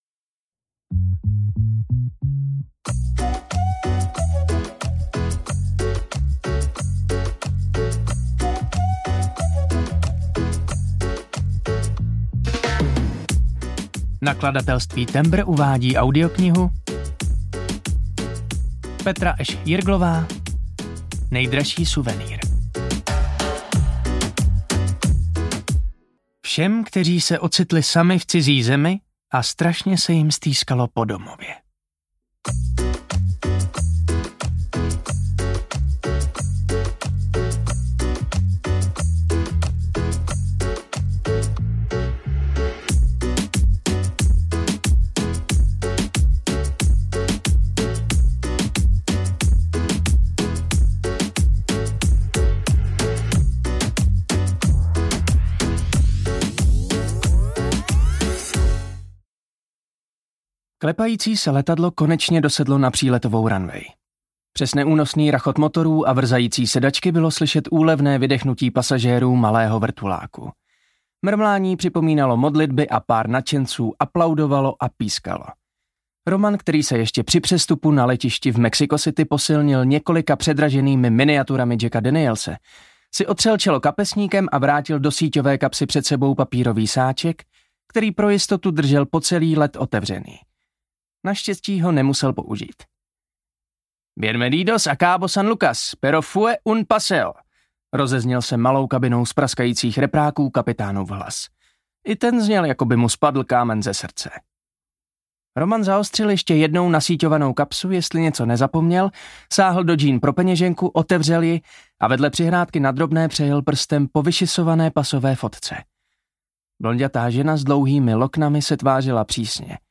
Nejdražší suvenýr audiokniha
Ukázka z knihy
nejdrazsi-suvenyr-audiokniha